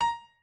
pianoadrib1_58.ogg